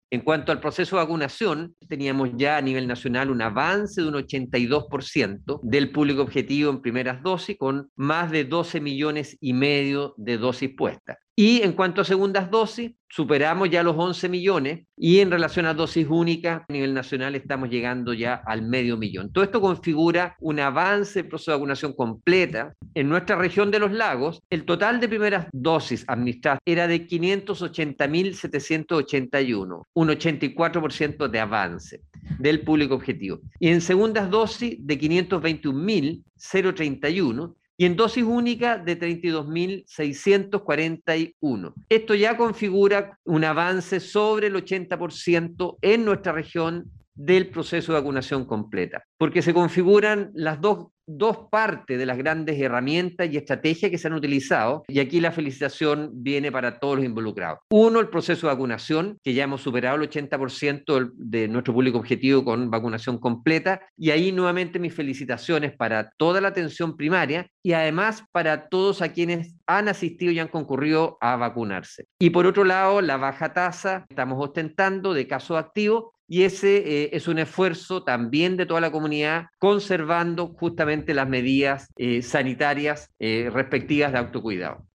Respecto al proceso de vacunación la autoridad de salud, indicó: